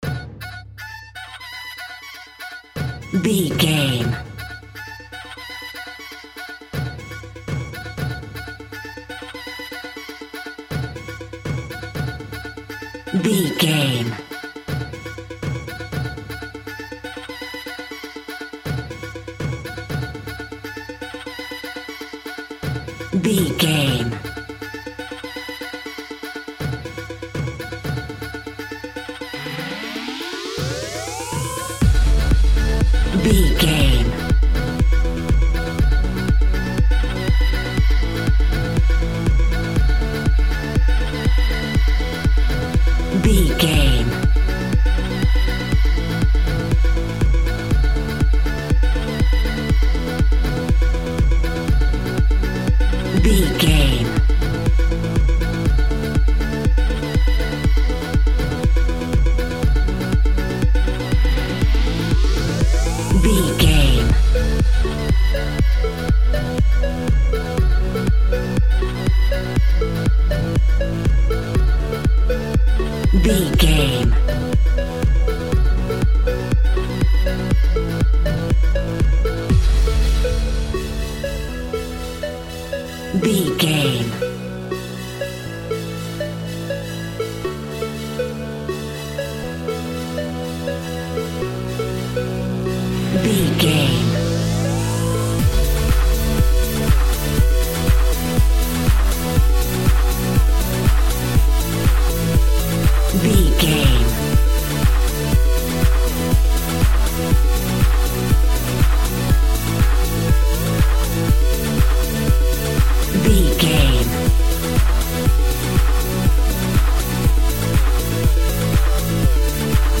Aeolian/Minor
F#
groovy
uplifting
driving
energetic
repetitive
synthesiser
drum machine
house
electro dance
techno
trance
synth leads
synth bass
upbeat